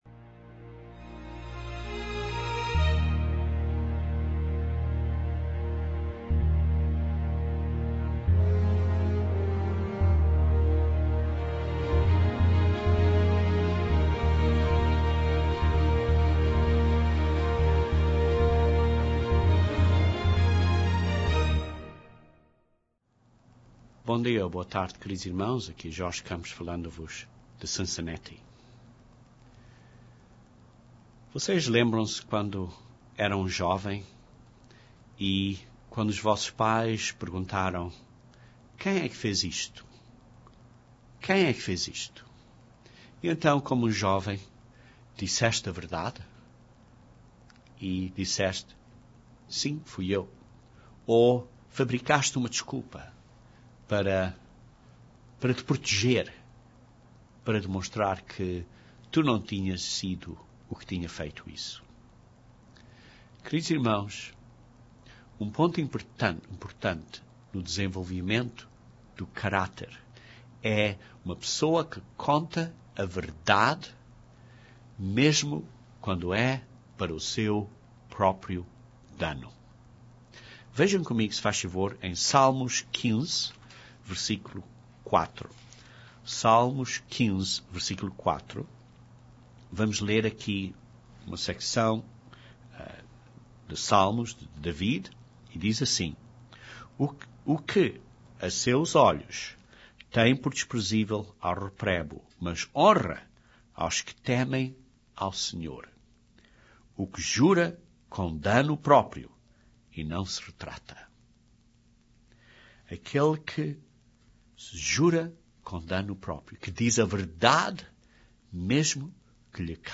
Mas caráter é uma coisa diferente. Este sermão descreve o que é caráter e como podemos desenvolver o Caráter de Deus na nossa vida.